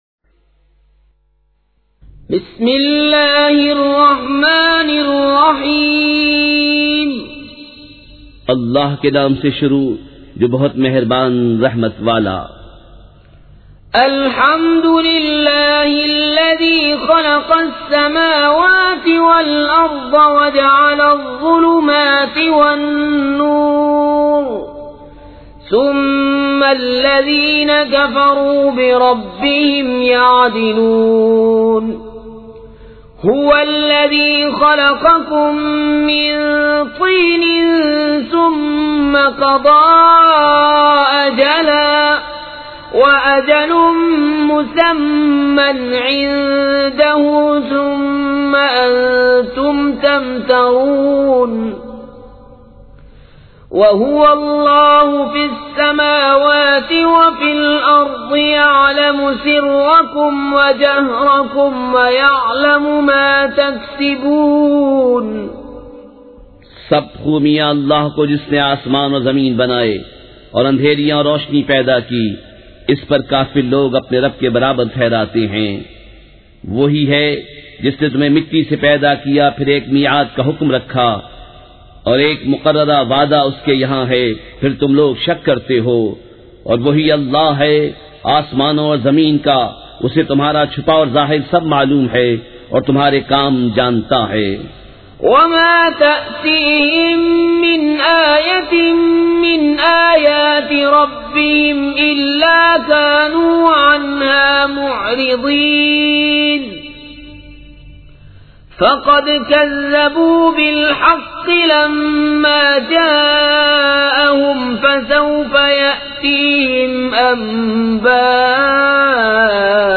سورۃ الانعام مع ترجمہ کنزالایمان ZiaeTaiba Audio میڈیا کی معلومات نام سورۃ الانعام مع ترجمہ کنزالایمان موضوع تلاوت آواز دیگر زبان عربی کل نتائج 4014 قسم آڈیو ڈاؤن لوڈ MP 3 ڈاؤن لوڈ MP 4 متعلقہ تجویزوآراء
surah-al-anaam-with-urdu-translation.mp3